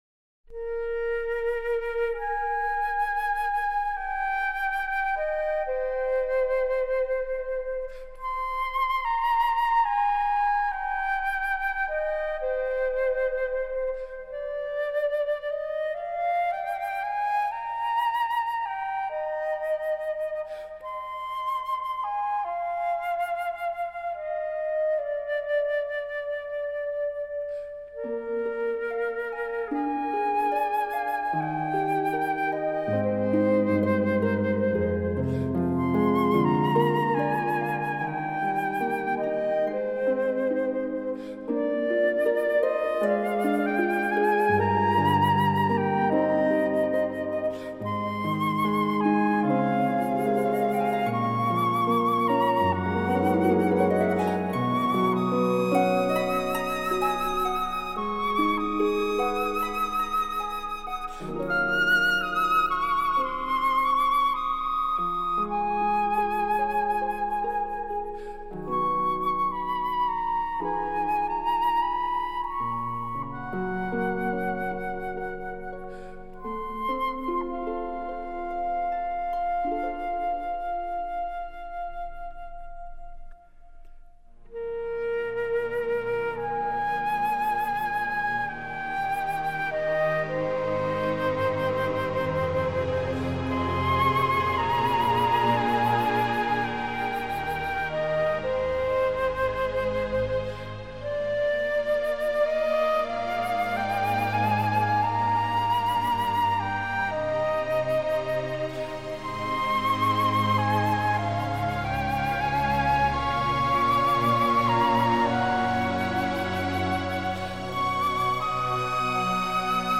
在輕盈的豎琴和柔和細膩管絃樂伴奏下